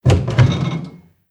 Cerrar la puerta de un aparador 02
Sonidos: Acciones humanas
Sonidos: Hogar